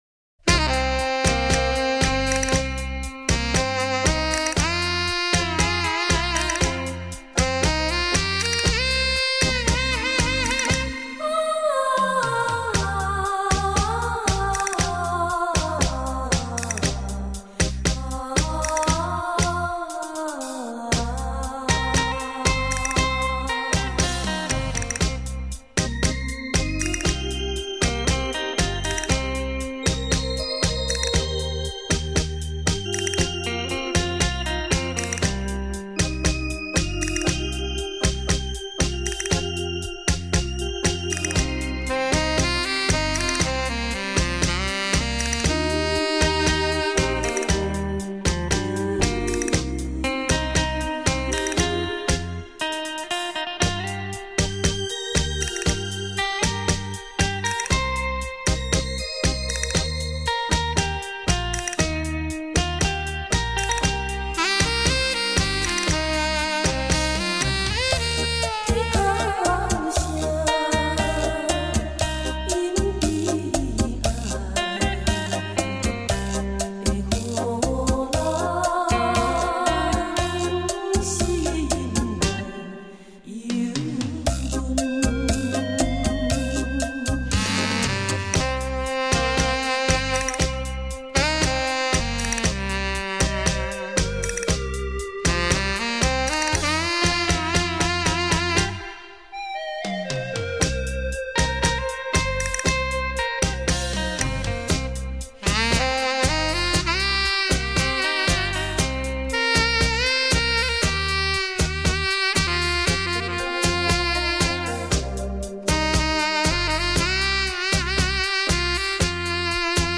雷射版[音响测试带] 现场演奏